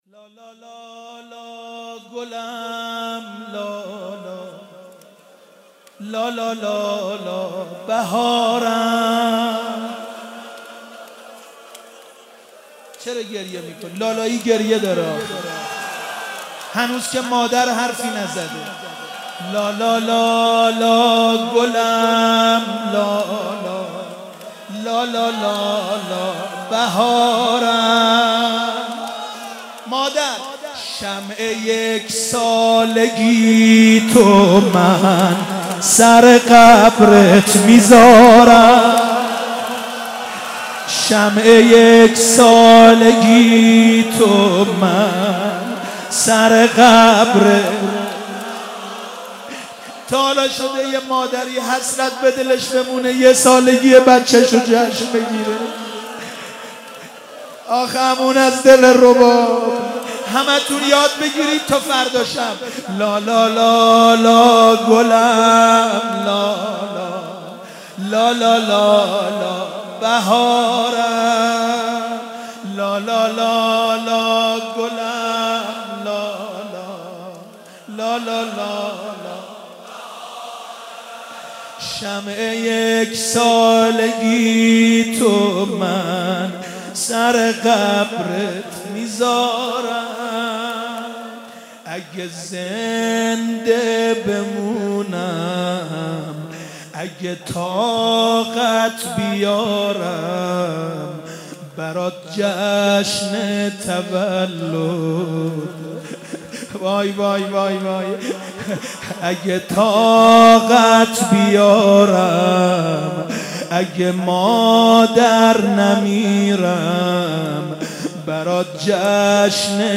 شب ششم محرم 95_روضه جانسوز حضرت علی اصغر علیه السلام